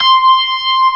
55o-org22-C6.aif